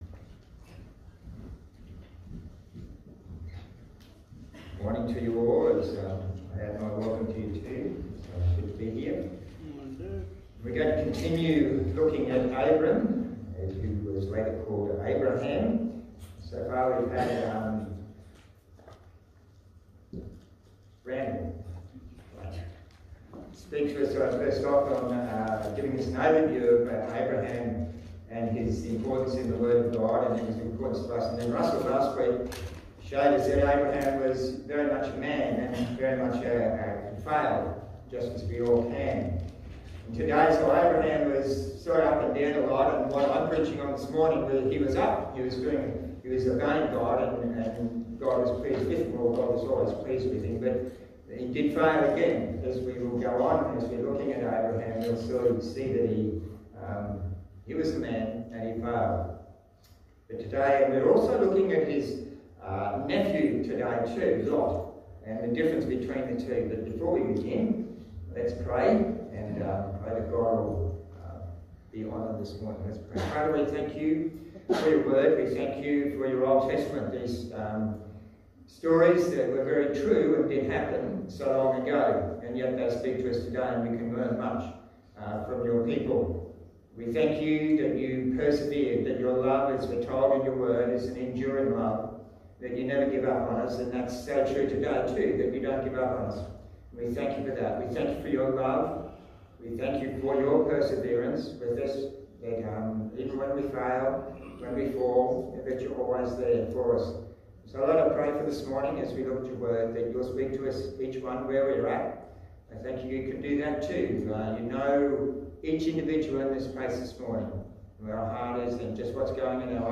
Passage: Genesis 13 Service Type: Sunday Morning